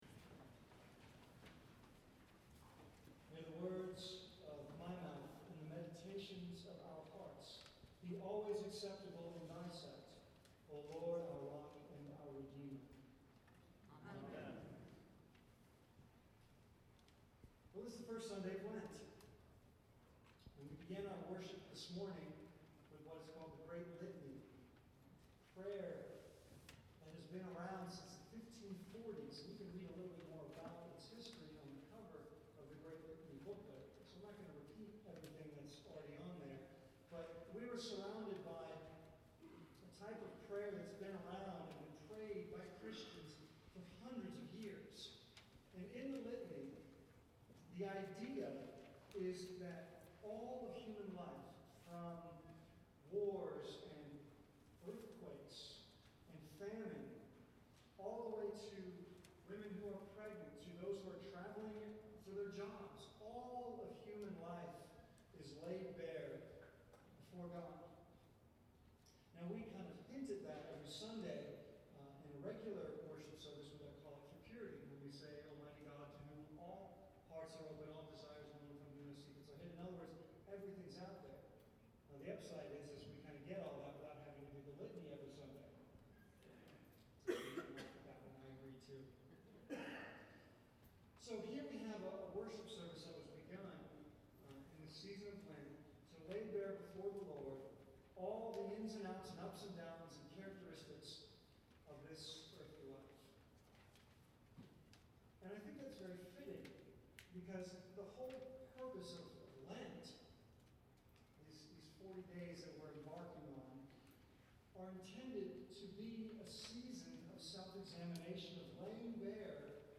It is necessary to understand the nature and depth of our fallen-ness, in order to fully understand the amazing nature and depth of God's grace to us. This sermon brings us into Lent by looking at the Fall of Adam and Eve and God's answer in the 'second Adam', Jesus Christ.